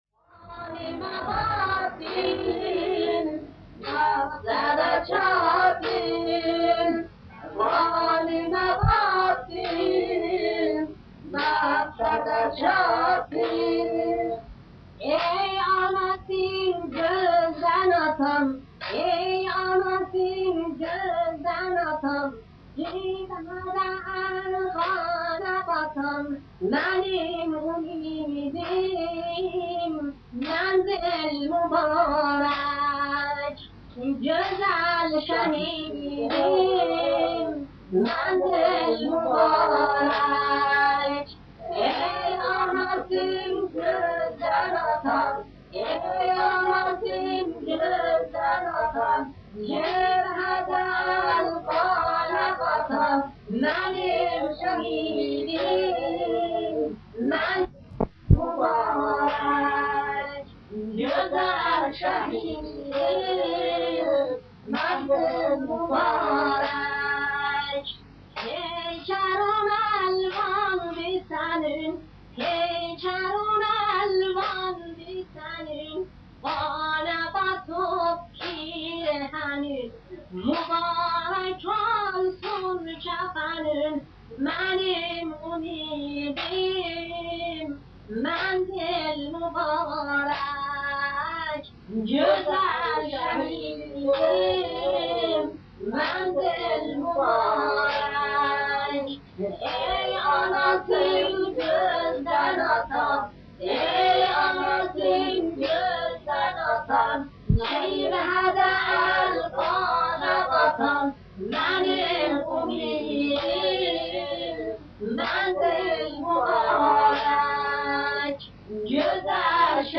نوای مداحی